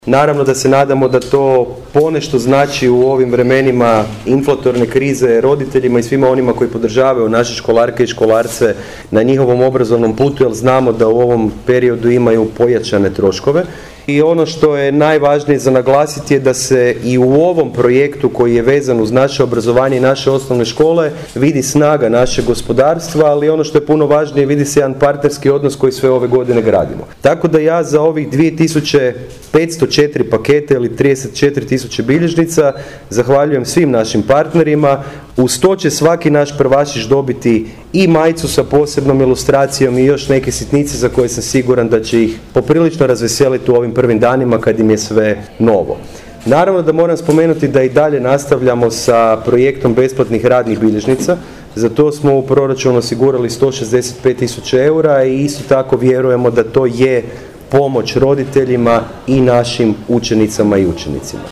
Zadovoljstvo projektom izrazio je i gradonačelnik Mišel Jakšić;